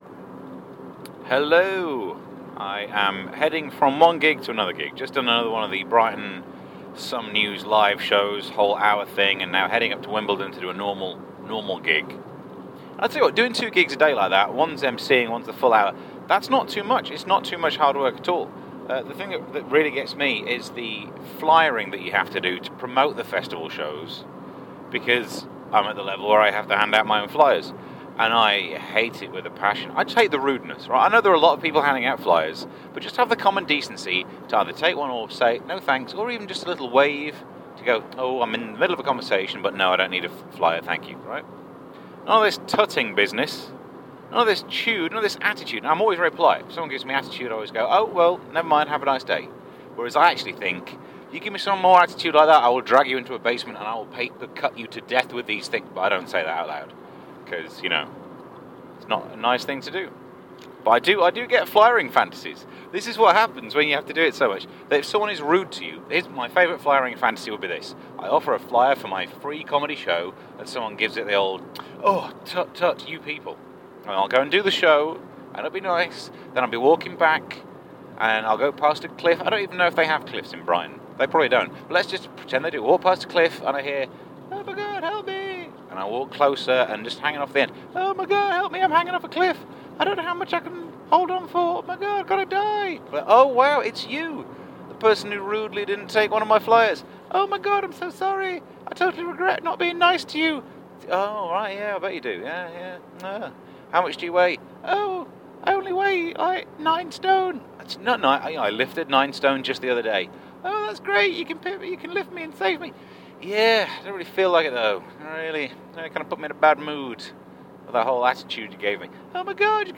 Driving from/to a gig.
On just way from my gig in Brighton, heading to the next one in Wimbledon, I had a little chat.